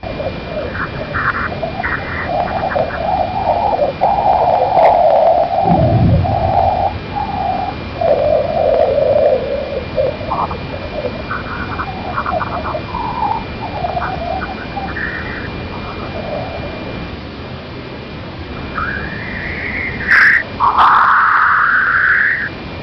Это электромагнитные колебания, переведенные в слышимый диапазон.
Шумы ионных частиц в атмосфере Юпитера